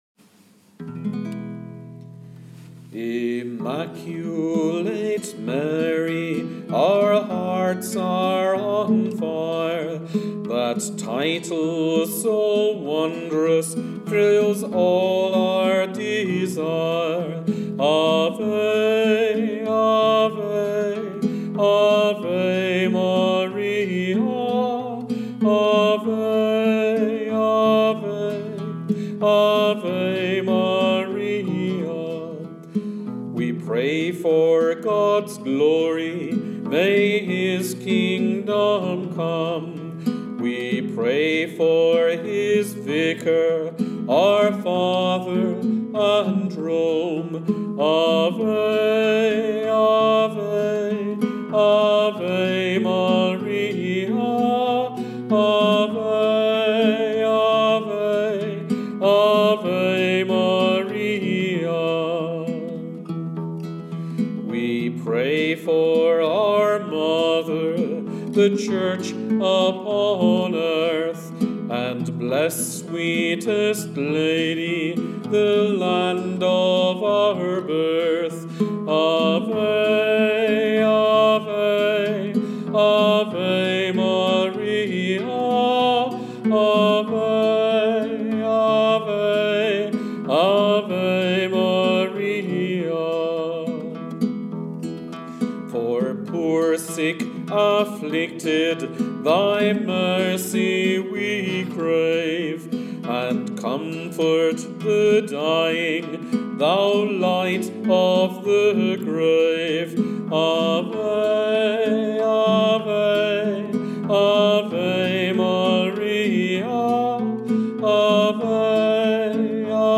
Hymn Marian lourdes hymn